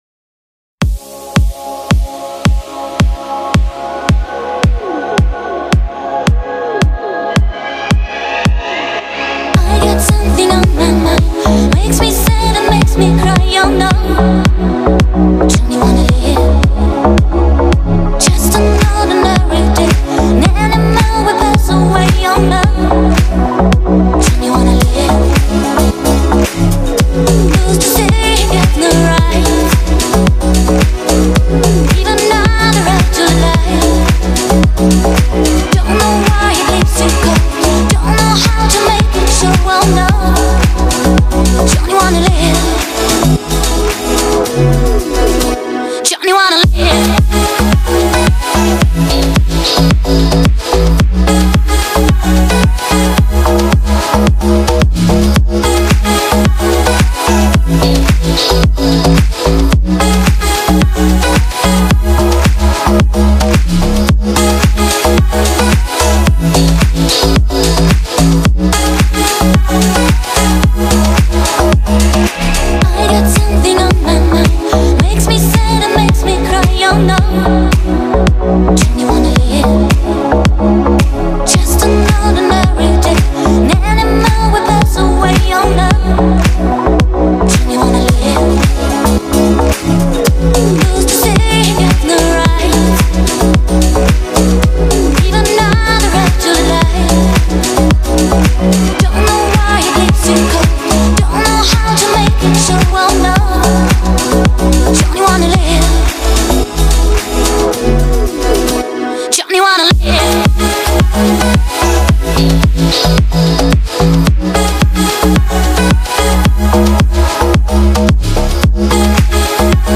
это электронная композиция в жанре synth-pop